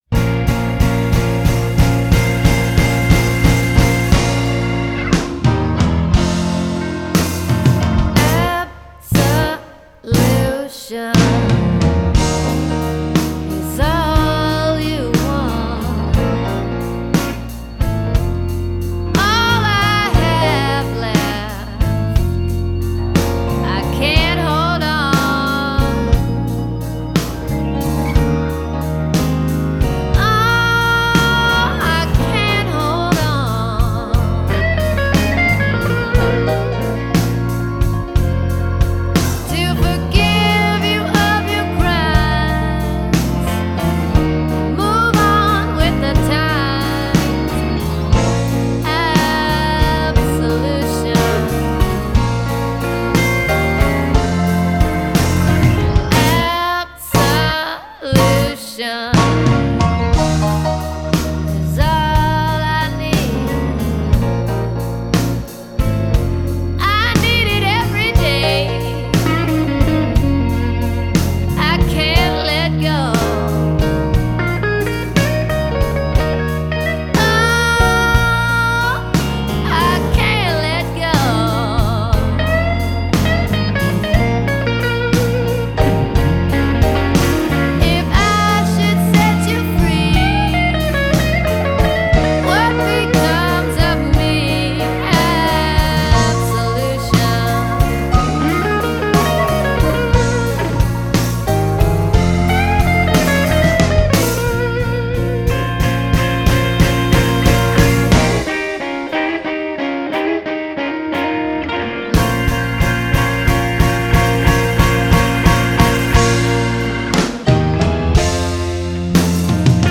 blues/roots